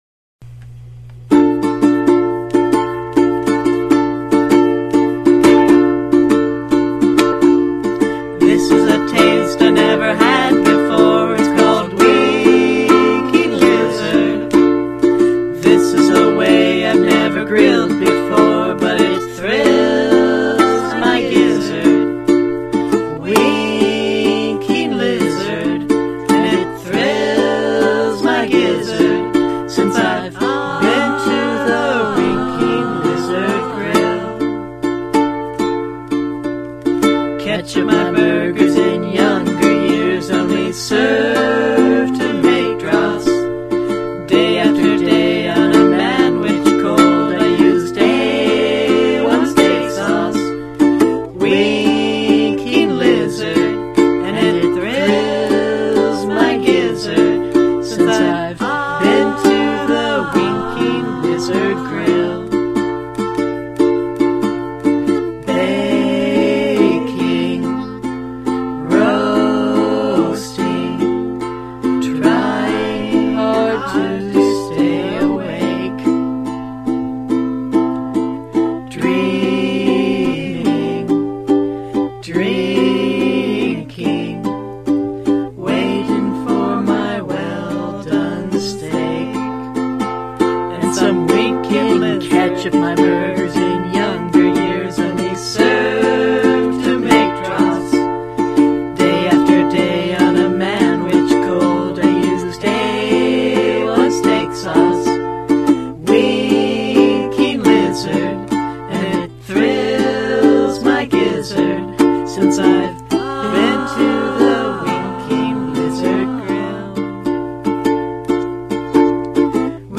Hurriedly, I pried it open with my claw hammer and was delighted to find a single-sided translucent flexi-disk, apparently an advertising premium from the Winking Lizard Tavern.